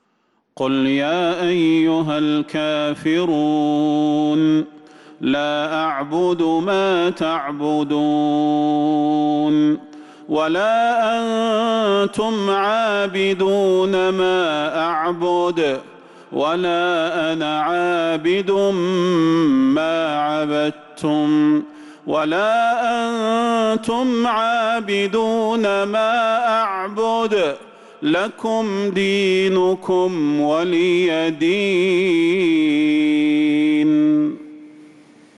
سورة الكافرون | جمادى الأولى 1447هـ > السور المكتملة للشيخ صلاح البدير من الحرم النبوي 🕌 > السور المكتملة 🕌 > المزيد - تلاوات الحرمين